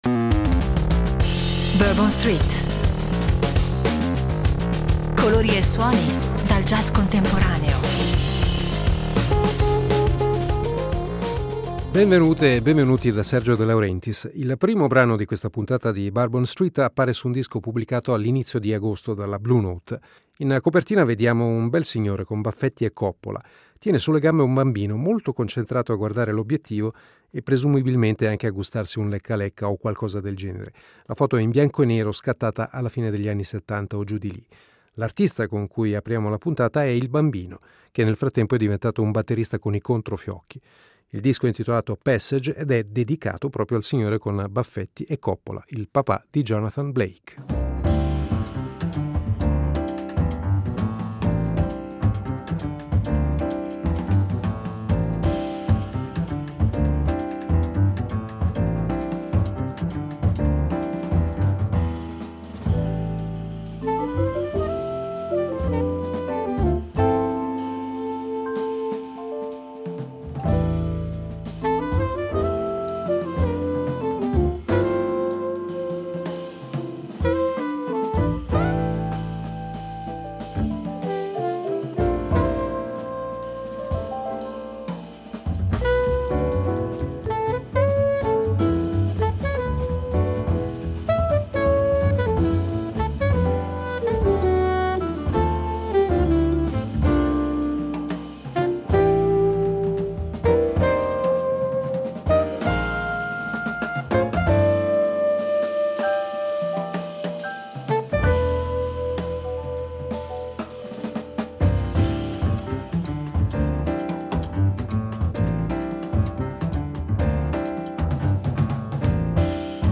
Bourbon Street Bacchette, fiumi e canzoni “stupide” Le bacchette più calde del momento ospiti in questa puntata di Bourbon Street 29.11.2023 46 min iStock Contenuto audio Disponibile su Scarica Questa puntata Bourbon Street ospita alcune delle bacchette più calienti dell’attuale scena jazzistica.